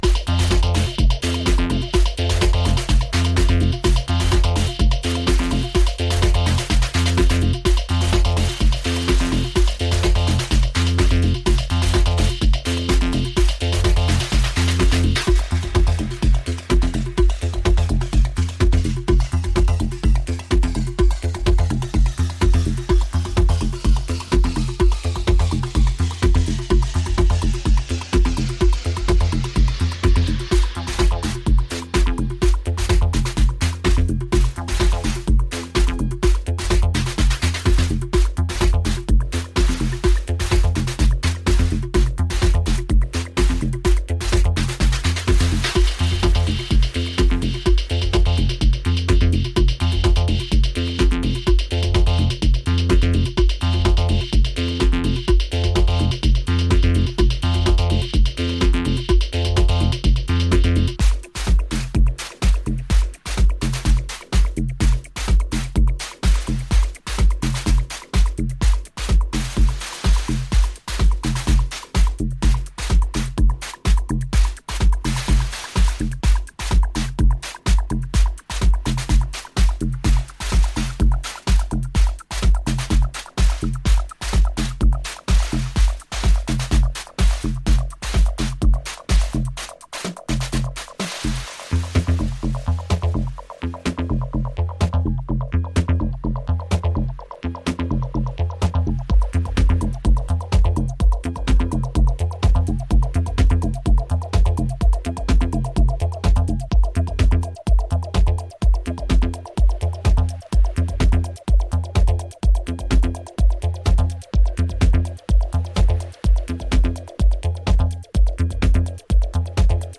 Used Acid House